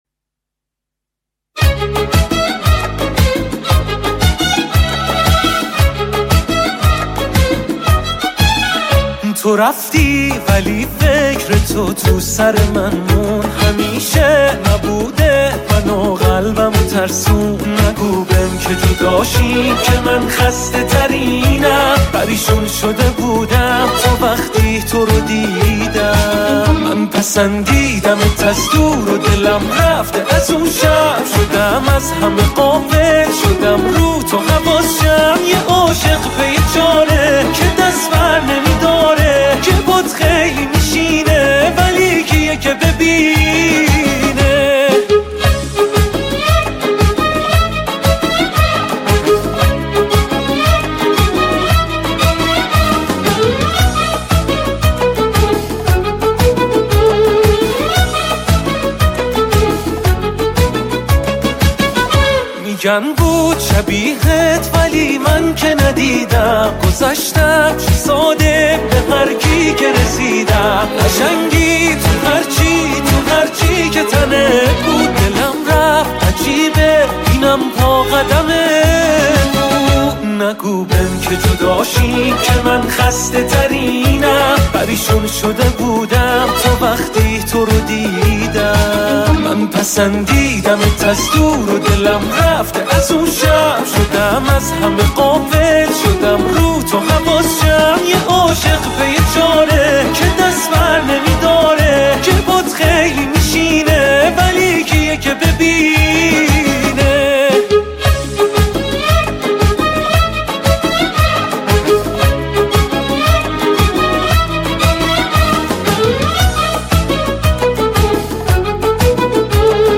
لذت‌بخش، هیجان‌انگیز و مناسب هر جمعی!